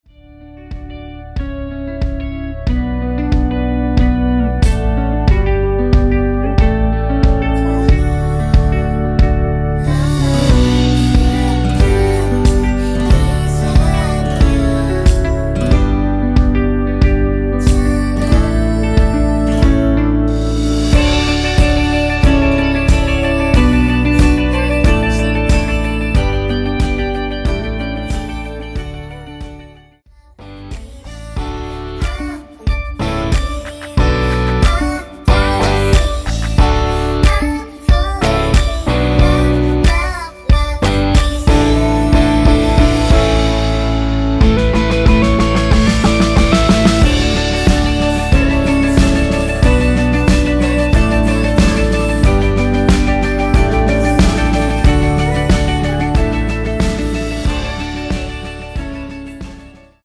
원키에서(+5)올린 코러스 포함된 MR입니다.
◈ 곡명 옆 (-1)은 반음 내림, (+1)은 반음 올림 입니다.
앞부분30초, 뒷부분30초씩 편집해서 올려 드리고 있습니다.
중간에 음이 끈어지고 다시 나오는 이유는